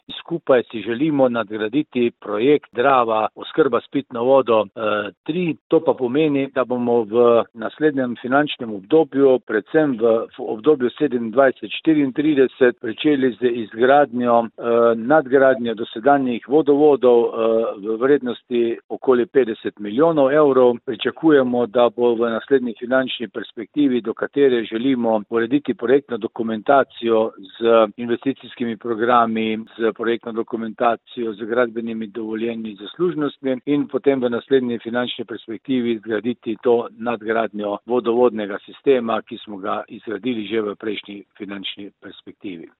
Župan občine Radlje ob Dravi Alan Bukovnik:
izjava Alan Bukovnik - vodovod  .mp3